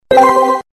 02 Shutter Sound 02.mp3